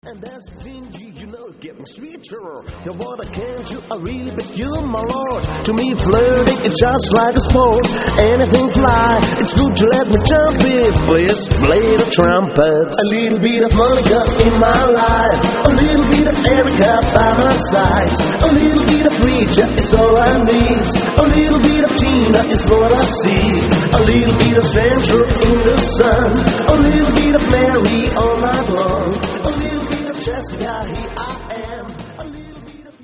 Party-Music-Band